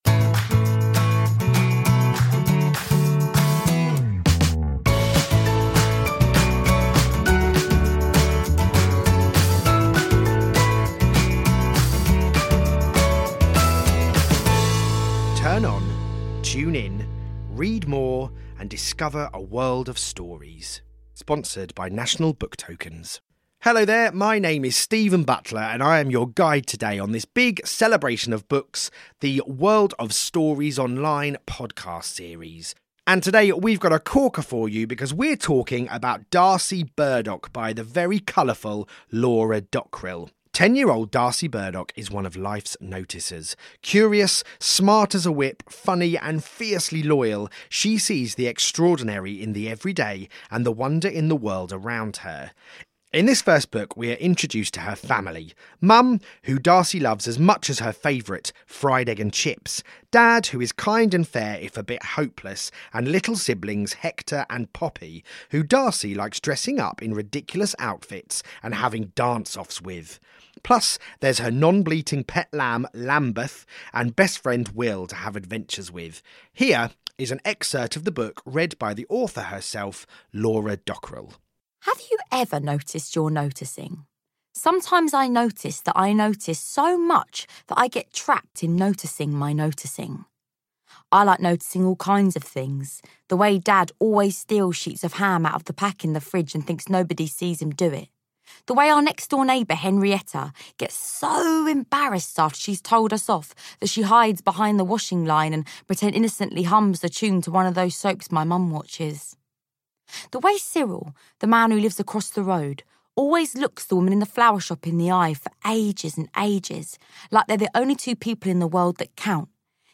Read by Laura Dockrill